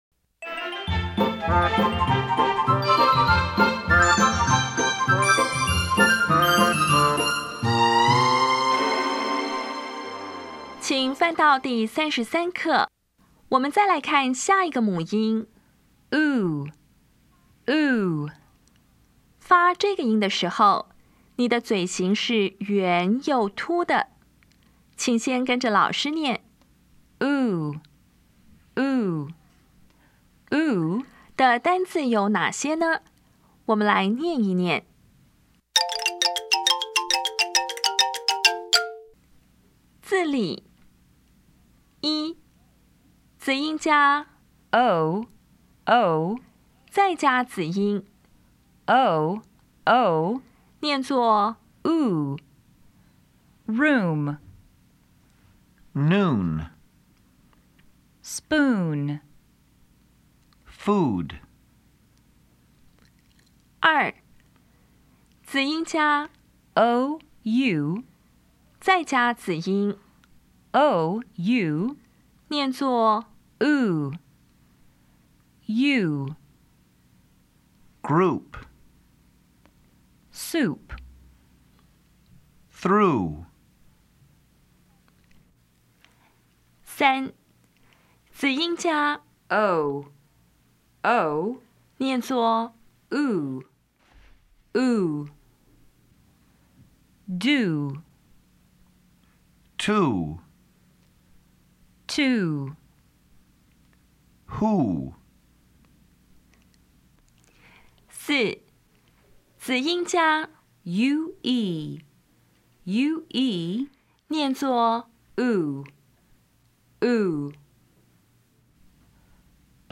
当前位置：Home 英语教材 KK 音标发音 母音部分-2: 长母音 [u]
音标讲解第三十三课
[rum]
比较[ʊ] 与 [u]